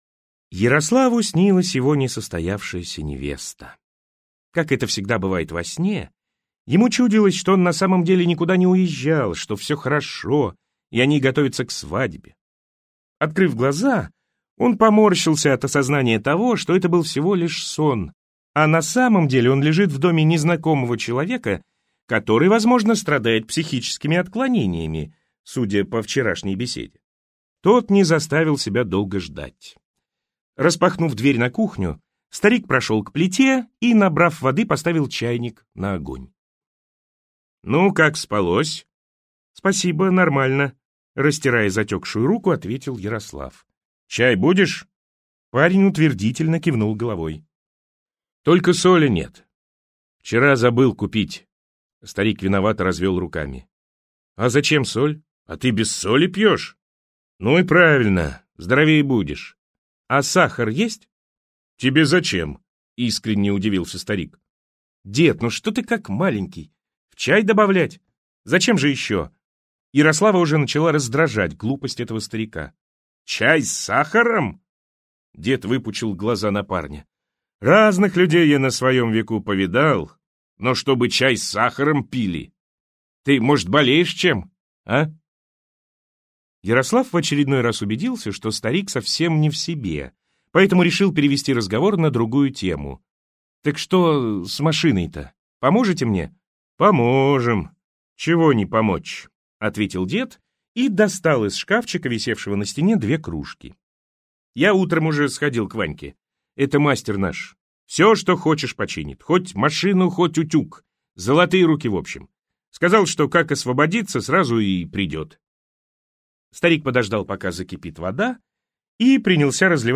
Аудиокнига Закрайсветовские хроники | Библиотека аудиокниг